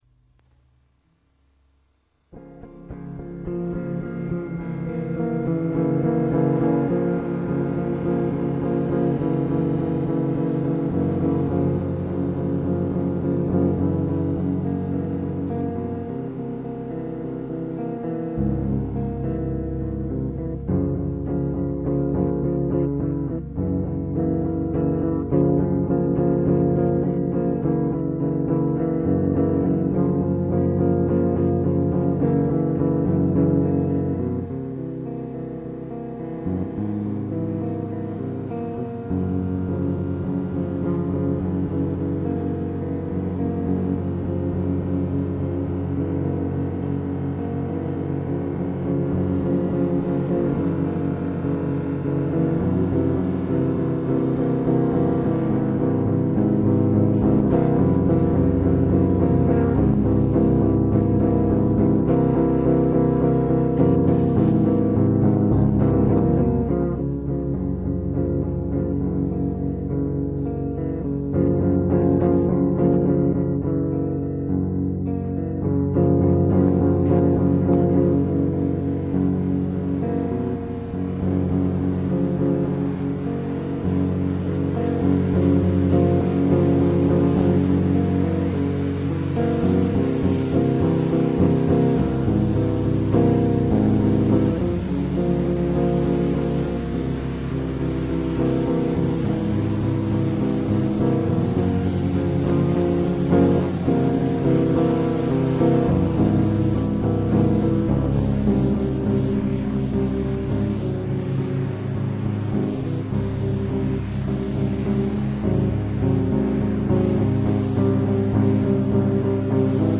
A little collage of song's fragment from band's practices: